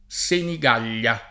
Senigallia [Senig#llLa] (pop. o lett. Sinigaglia [Sinig#l’l’a]; antiq. Senigaglia [